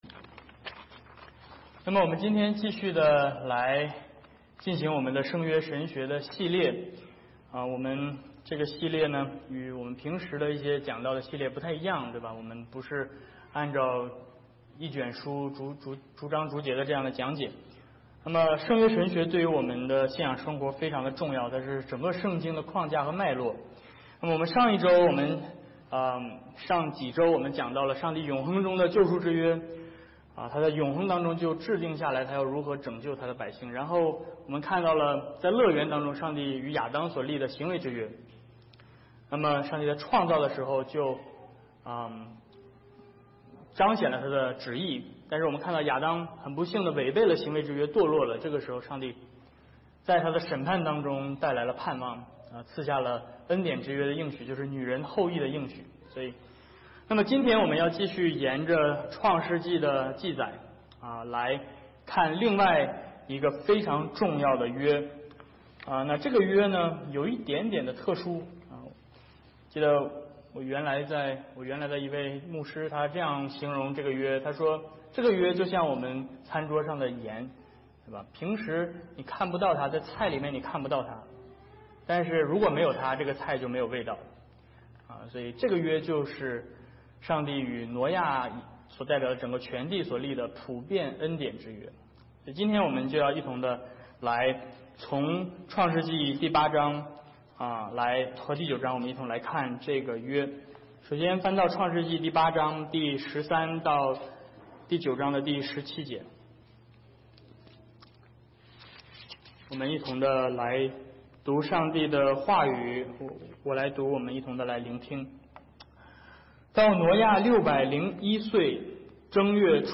Passage: Genesis8:13-9:17 Service Type: 主日讲道